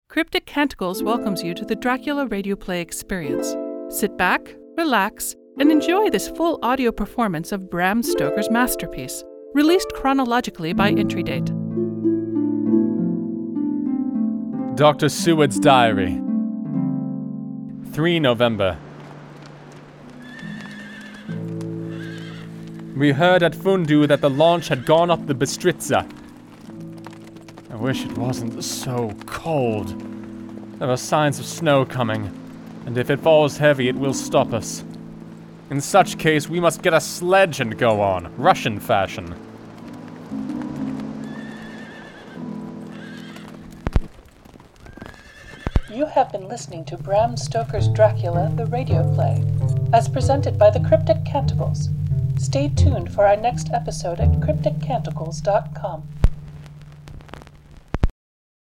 Audio Engineer, SFX and Music